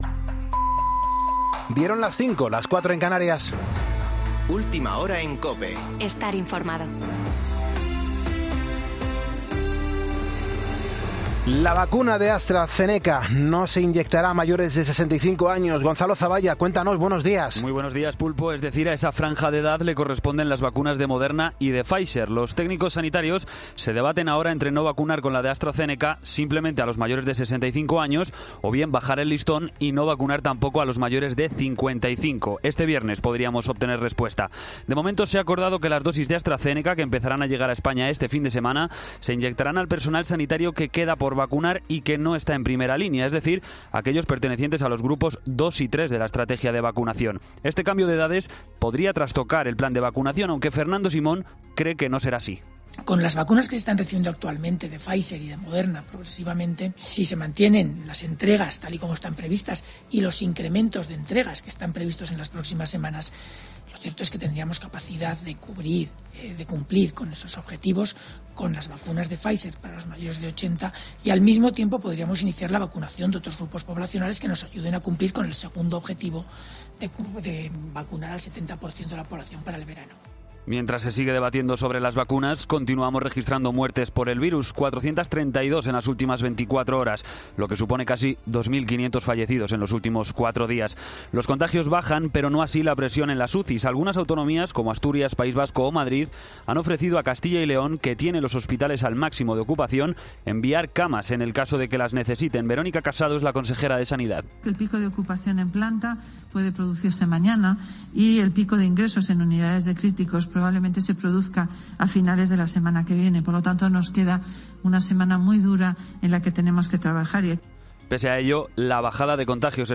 Boletín de noticias COPE del 5 de febrero de 2021 a las 05.00 horas
AUDIO: Actualización de noticias 'Herrera en COPE'